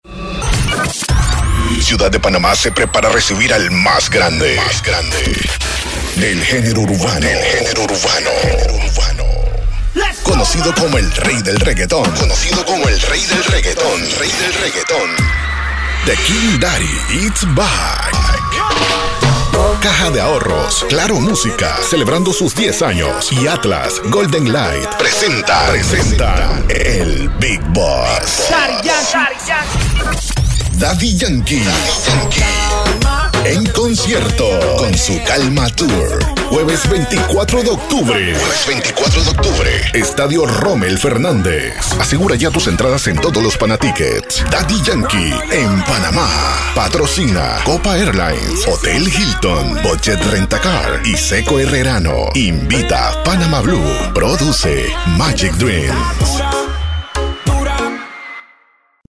Voz Potente y VersĂĄtil
spanisch SĂŒdamerika
Sprechprobe: Sonstiges (Muttersprache):